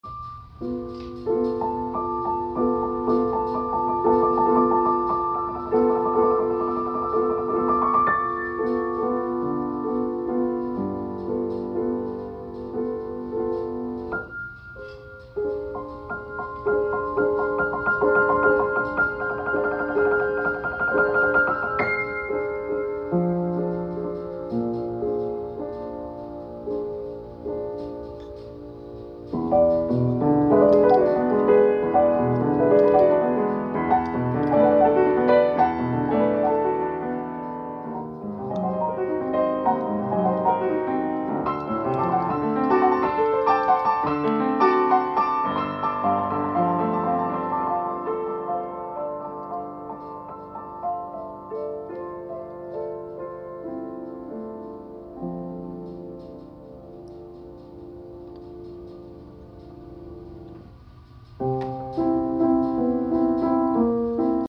Unearthed from the drafts cause it’s a sloppy performance but a beautiful piece.